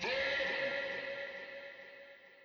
Yall Know Vox.wav